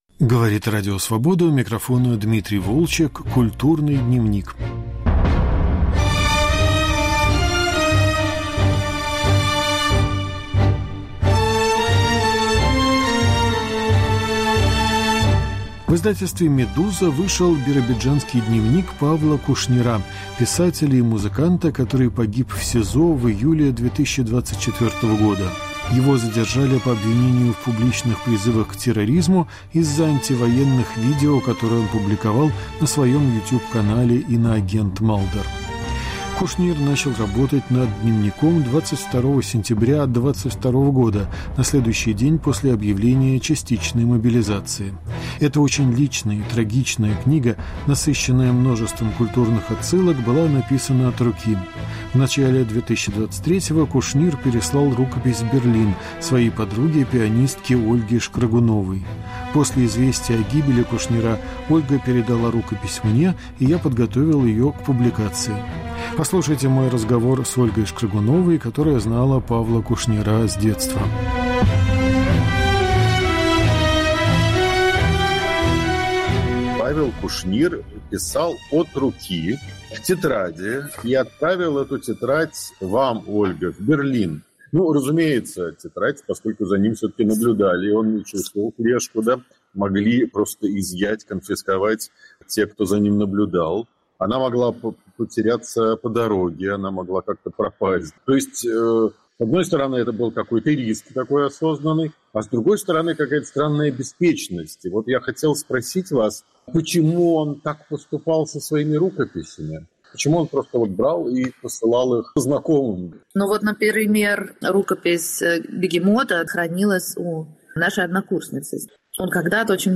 Разговор